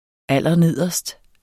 Udtale [ ˈalˀʌˈneðˀʌsd ]